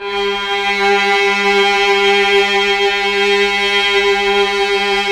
Index of /90_sSampleCDs/Keyboards of The 60's and 70's - CD1/KEY_Chamberlin/STR_Chambrln Str
STR_Chb StrG_4-L.wav